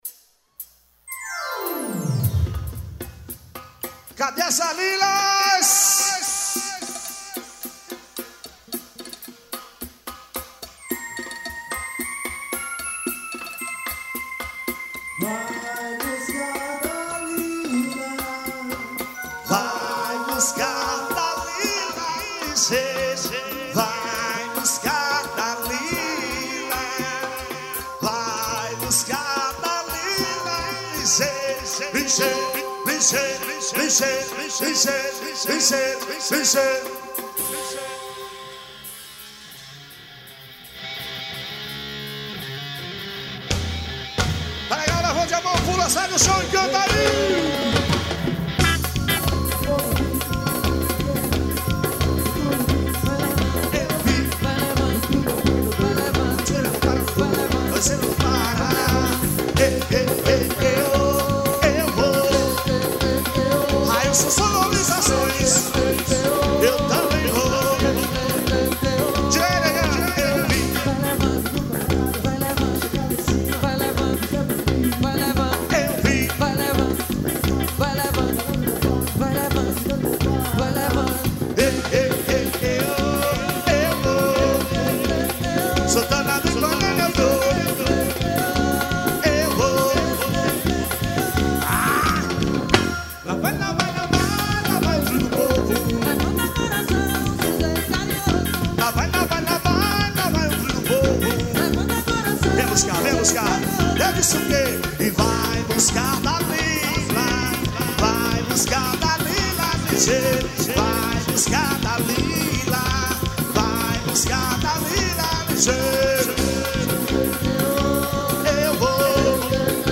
AXÉ.